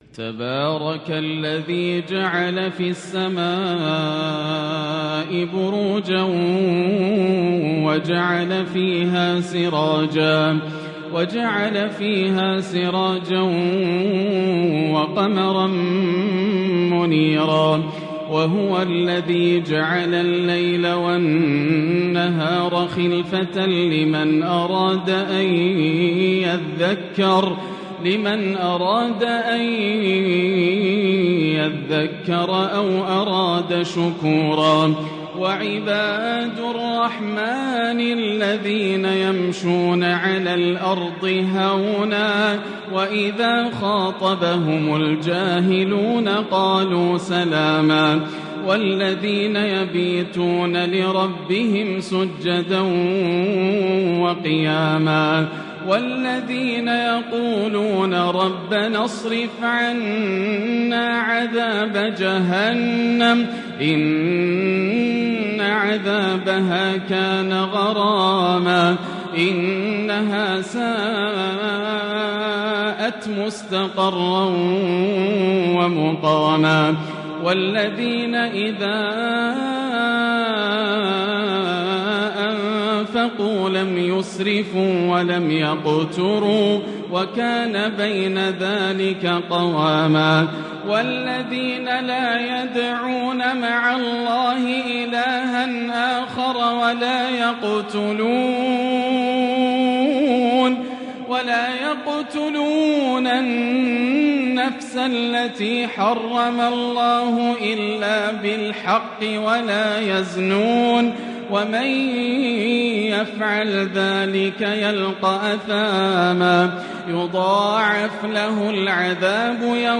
حبرها تحبيرا | تستقر في القلب من آخر سورة الفرقان للشيخ ياسر الدوسري | عشاء ٩ ذو القعدة ١٤٤٣ > تلاوات عام 1443هـ > مزامير الفرقان > المزيد - تلاوات الحرمين